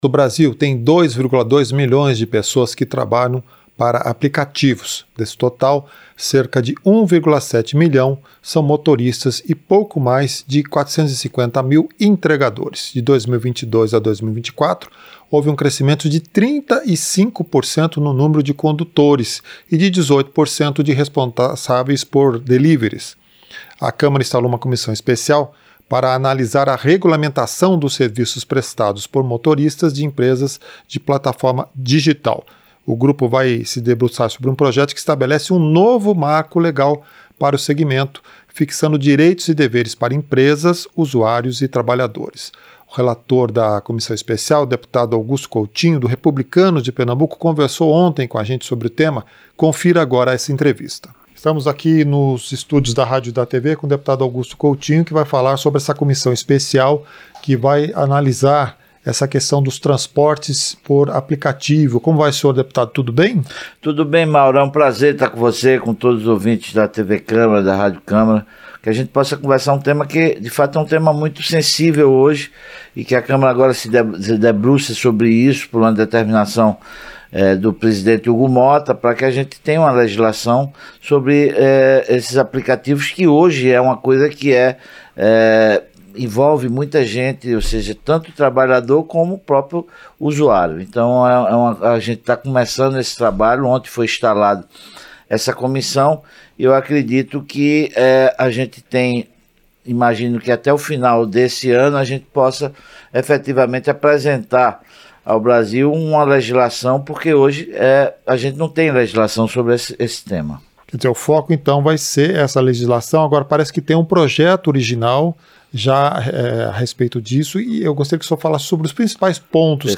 Entrevista - Dep. Augusto Coutinho (Rep-PE)